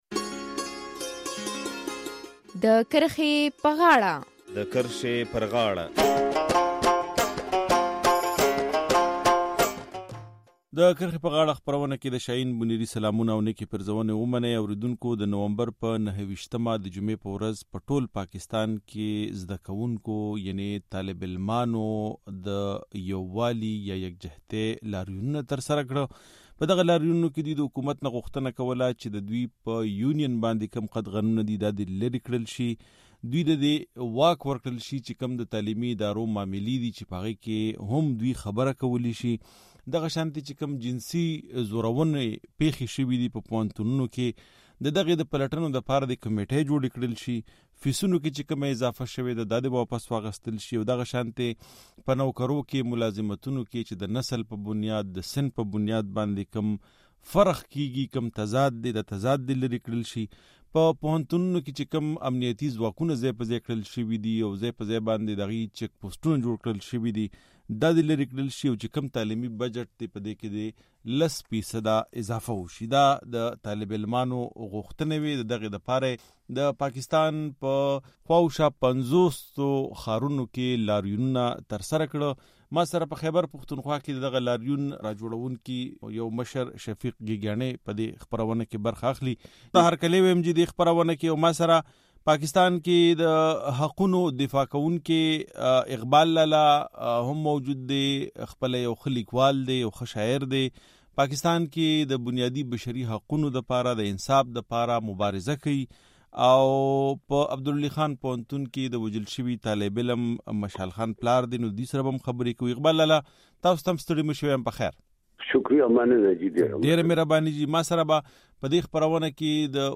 د نومبر پر۲۹مه د پاکستان په بېلا بېلو برخو کې زده کوونکیو د دوی په یونین د قدغنونو، جنسي زورونو او فیسونو کې د زیاتوالي پرضد لاریونونه ترسره کړل. د زده کوونکو لاریونونو تر شا څه دي؟ او تر اوسه ریاست د دغه ستونزو په هواري کې ولې پاتې راغلې دی؟ په دې اړه د کرښې پر غاړه کې بحث کوو.